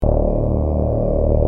Sequencial Circuits - Prophet 600 49